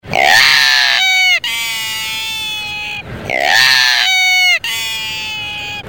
Albatros fuligineux. Baie Larose.
Le cri de l'albatros fuligineux est une note déchirante qui s'accorde parfaitement avec la sombre beauté des îles Kerguelen.